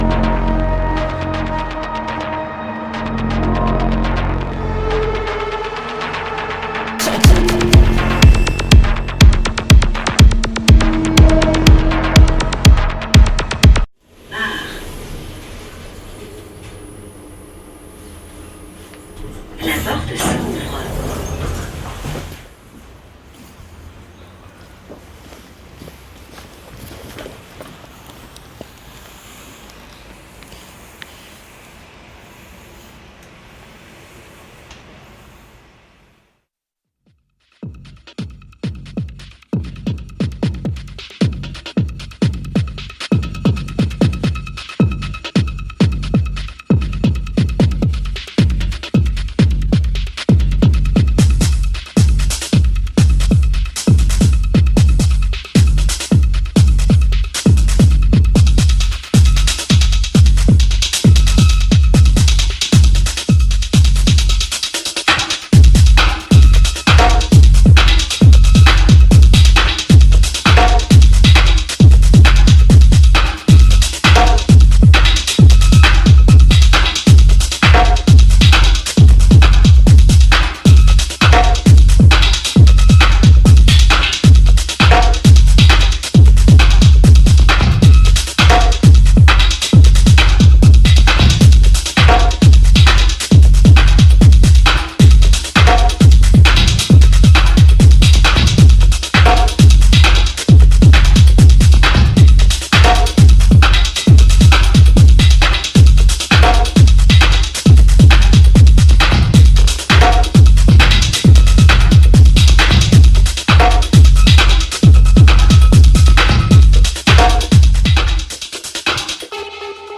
Liveset
Genre: Techno